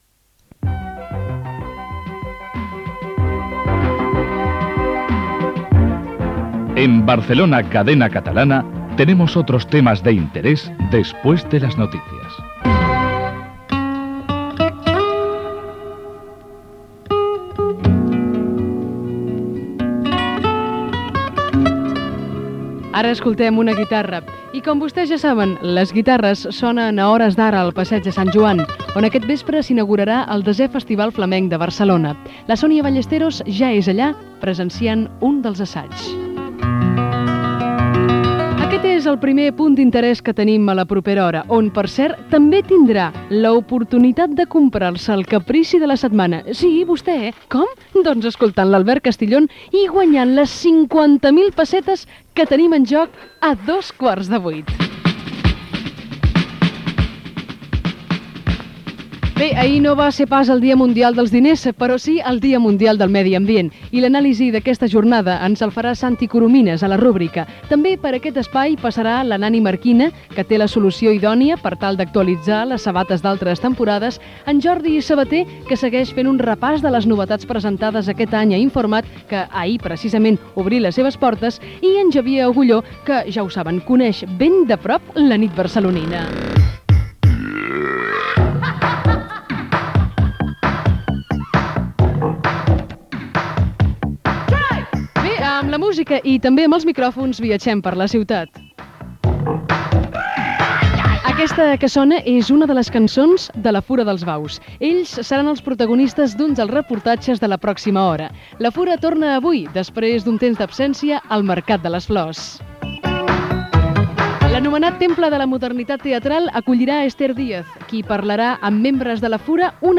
Avenç de continguts, ciències ocultes, cultura, el cinema, la cuina, urbanisme, la programació televisiva Gènere radiofònic Informatiu
Programa pilot de la radiofórmula Barcelona Cadena Catalana (BCC).